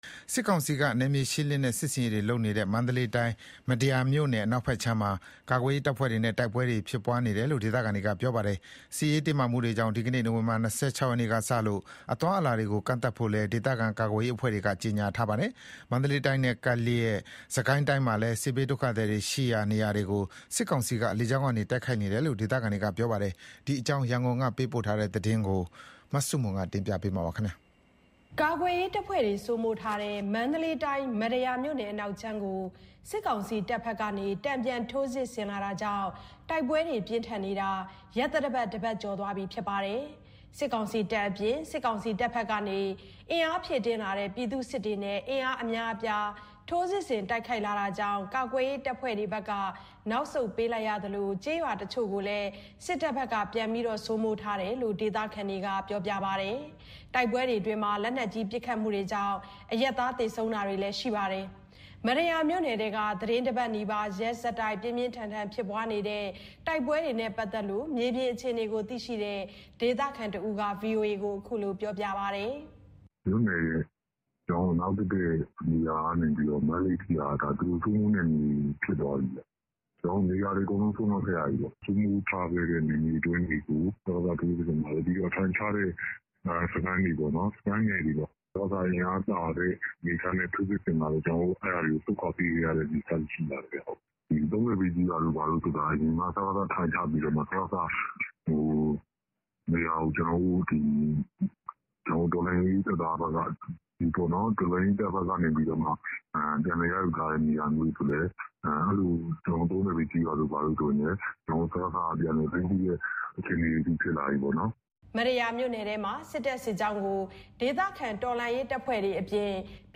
မတ္တရာမြို့နယ်ထဲက သီတင်းတပတ်နီးပါး ရက်ဆက်တိုက် ပြင်းပြင်းထန်ထန် ဖြစ်ပွားနေတဲ့ တိုက်ပွဲတွေနဲ့ပတ်သက်ပြီးမြေပြင်အခြေအနေကိုသိရှိတဲ့ ဒေသခံတဦးက အခုလို ပြောပါတယ်။
စစ်ကိုင်းမြို့နယ်ထဲက တလိုင်းကျေးရွာမှာ မတ္တရာမြို့နယ်ထဲက တိုက်ပွဲတွေကြောင့် စစ်ကိုင်းဘက်ကို တိမ်းရှောင်လာတဲ့စစ်ဘေးရှောင်တွေရှိပြီး လေကြောင်းပစ်ခတ်တာကြောင့် ဘေးလွတ်ရာ နေရာတွေဆီကို ထပ်မံတိမ်းရှောင်နေရတယ်လို့ဒေသခံတဦးက အခုလို ပြောပါတယ်။